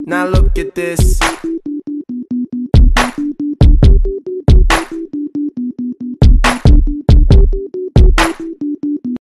This interview segment was actually sound effects free download